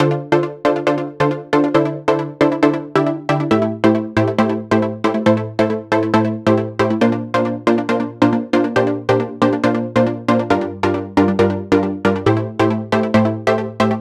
VTS1 Universe Kit 137BPM Melody Intro DRY.wav